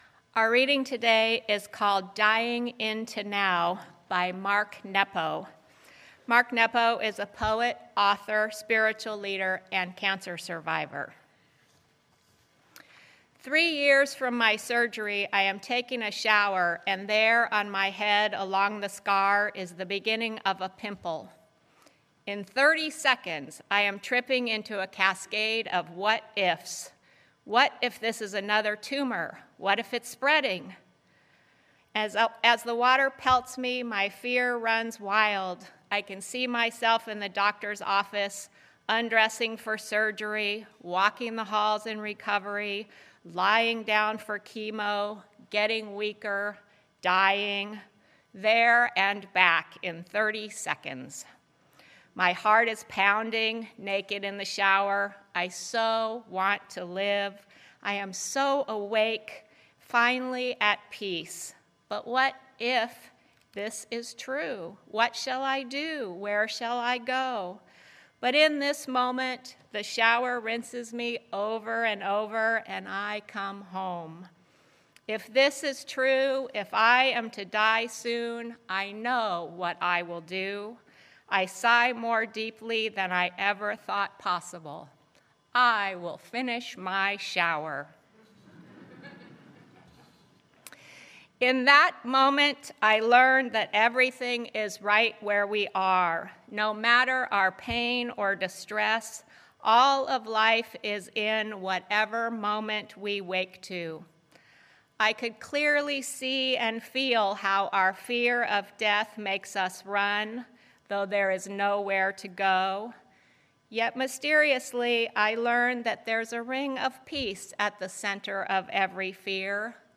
0:00 Reading 2:29 Homily part 1 14:07 Homily part 2
Sermon-A-Good-Death.mp3